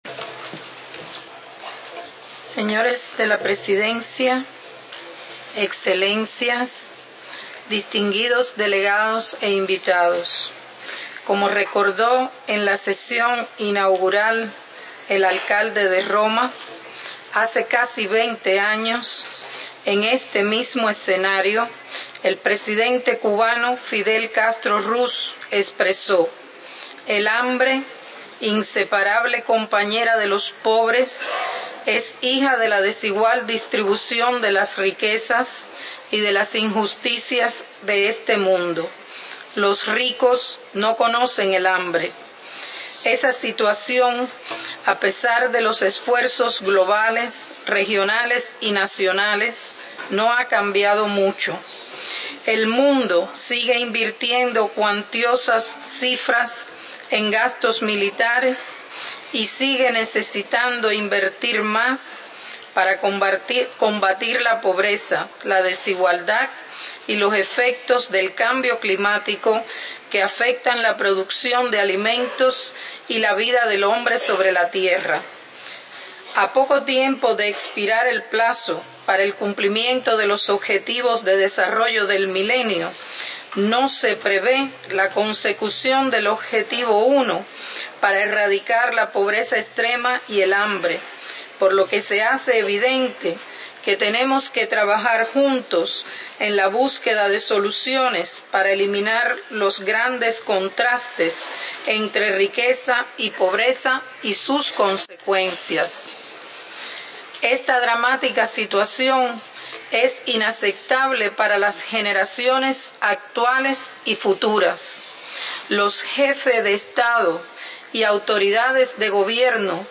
Second International Conference on Nutrition (ICN2), 19-21 November 2014
Discurso de la Excma. Sra. Doña Milagros Carina Soto Agüero
Embajadora y Representante Permanente de la República de Cuba ante la FAO